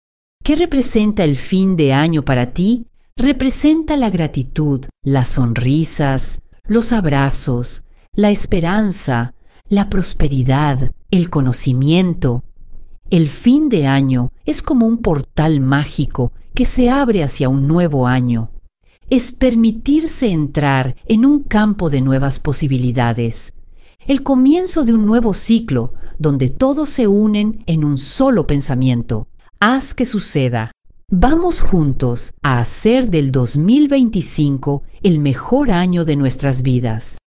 Serviços profissionais de Locução Nativa em Espanhol
AMOSTRAS - VOZ FEMININA